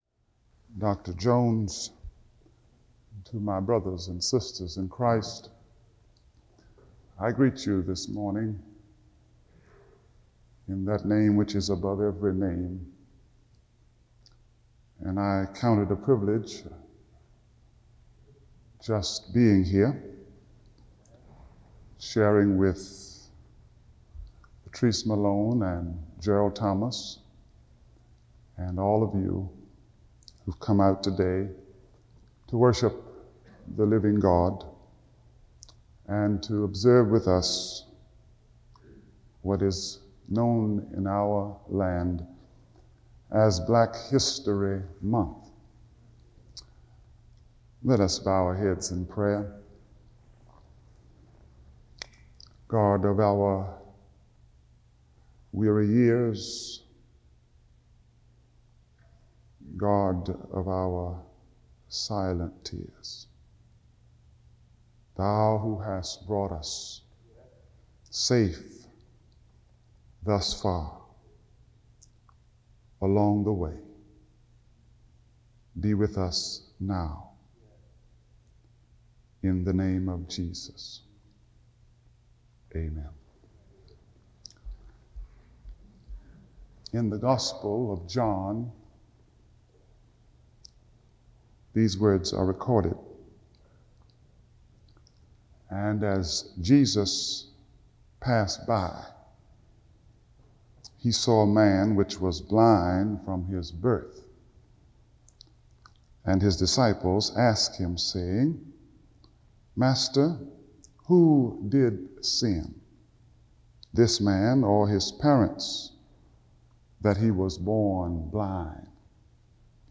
Chapel Address